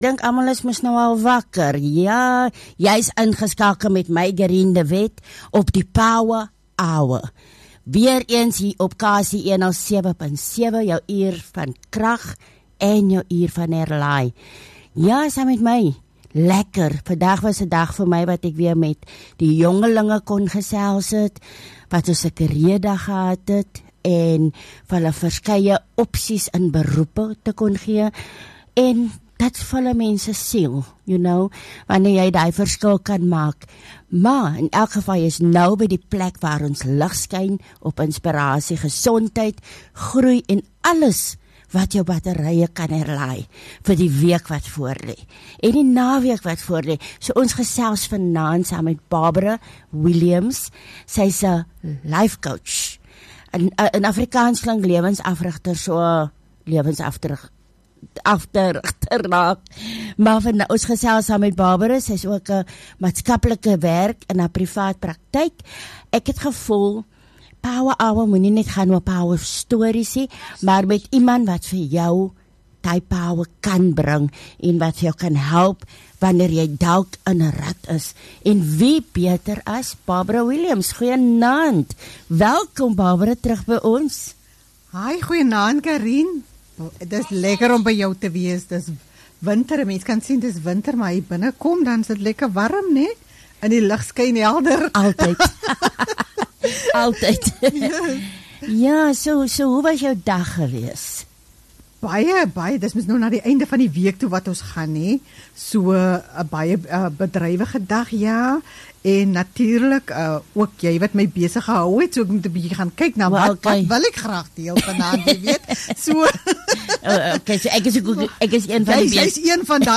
Onderhoud